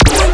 fire_particle1.wav